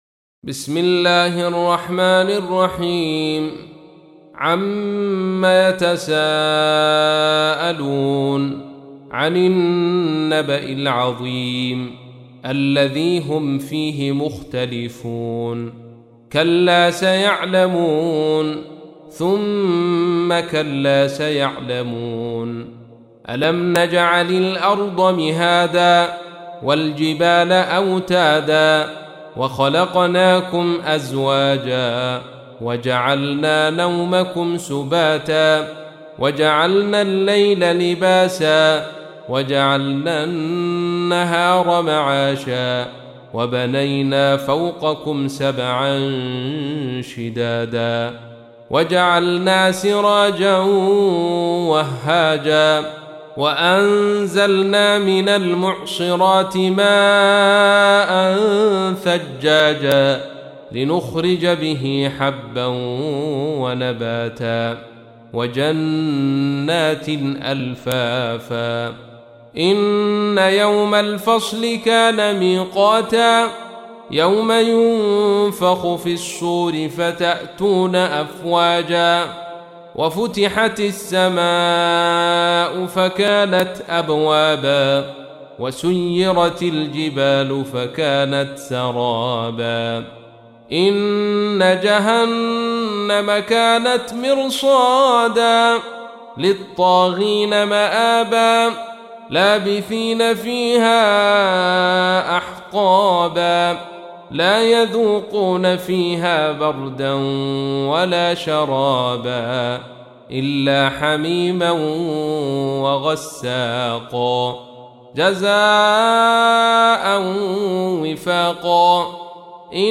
تحميل : 78. سورة النبأ / القارئ عبد الرشيد صوفي / القرآن الكريم / موقع يا حسين